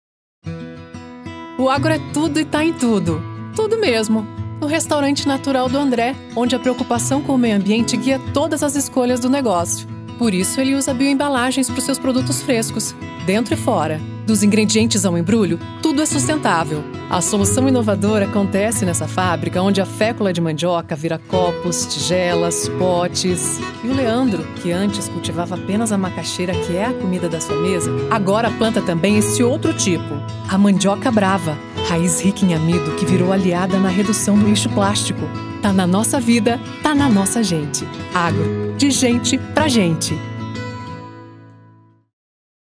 Institucional: